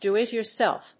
アクセントdó‐it‐yoursélf
音節dò-it-yoursélf発音記号・読み方dùː-